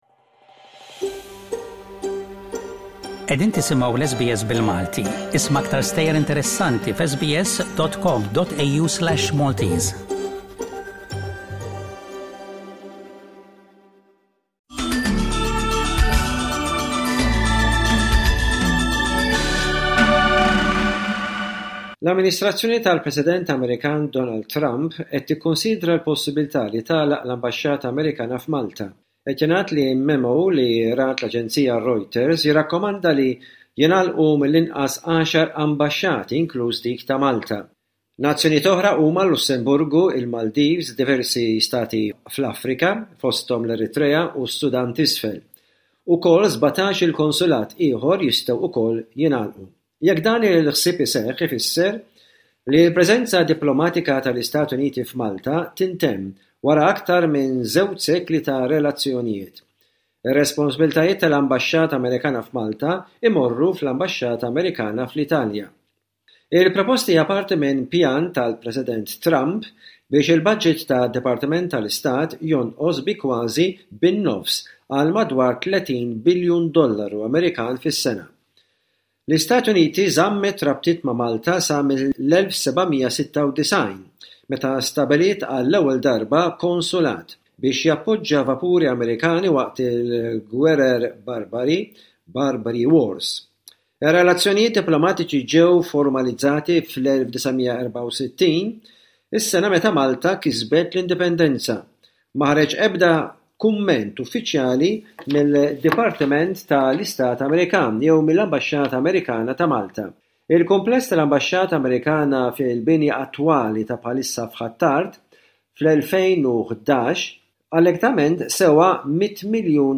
Aħbarijiet minn Malta: 18.04.25